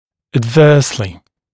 [æd’vɜːslɪ][эд’вё:сли]неблагоприятно